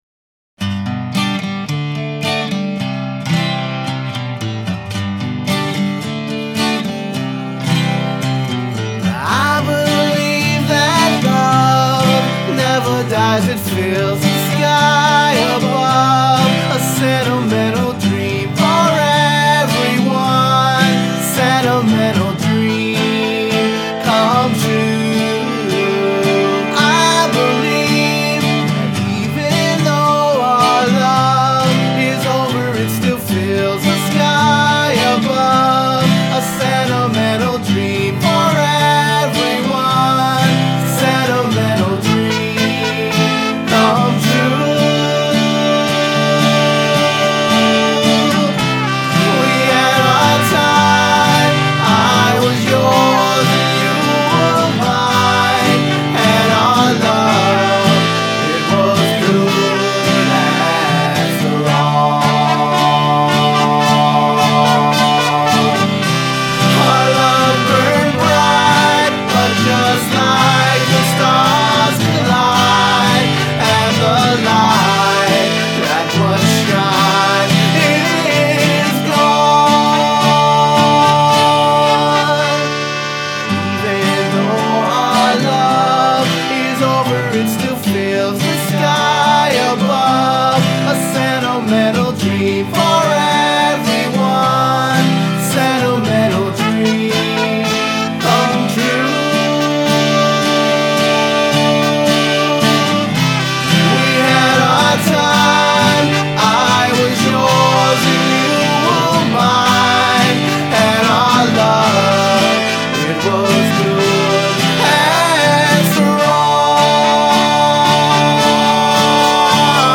Vocals, Guitars, Bass, Harmonica, Trumpet